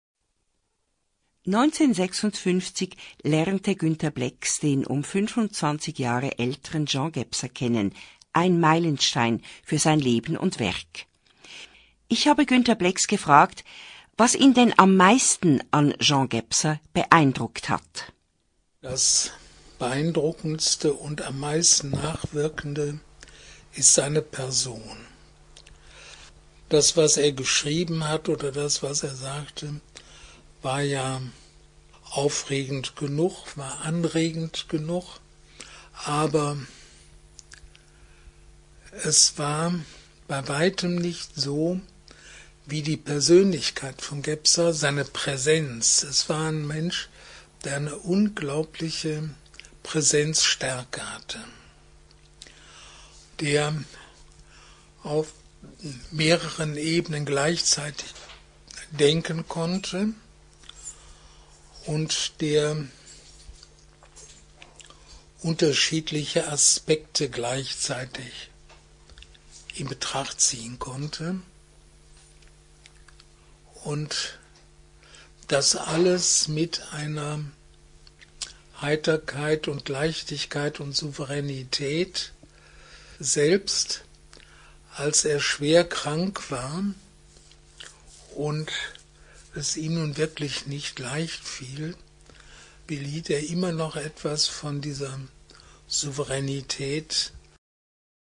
CD Ein Freund von Jean Gebser erzählt. (Hörprobe)